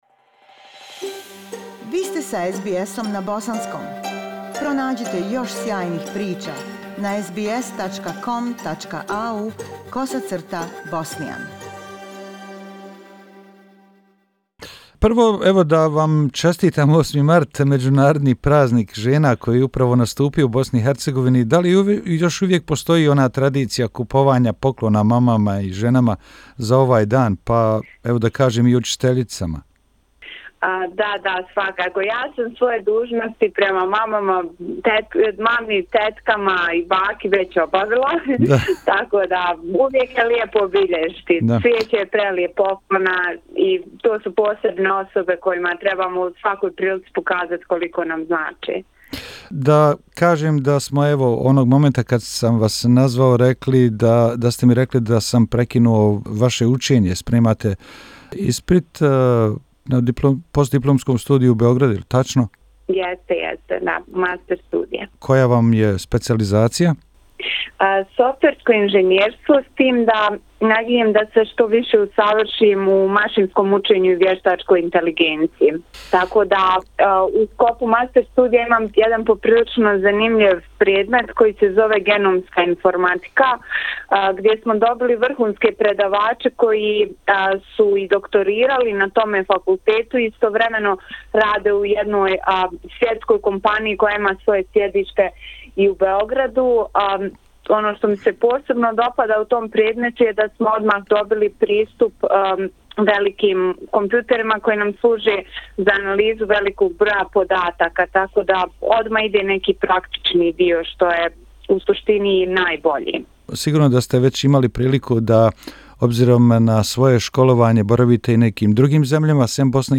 Razgovor sa dvoje mladih ljudi koji su odlučni, pametni i spremni za promjene